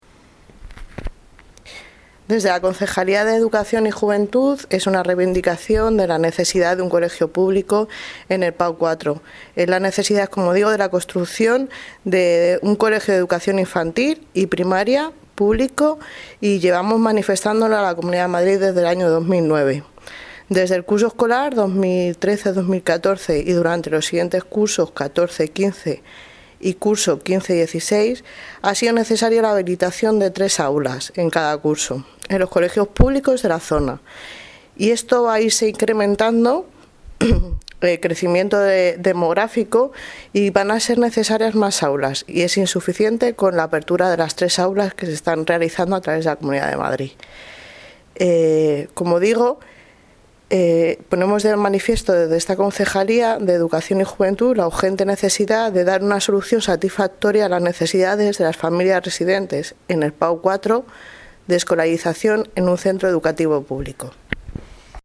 Audio - Aranzazu Fernández (2ª Teniente de Alcalde) Sobre ofrecimiento Parcela para Colegio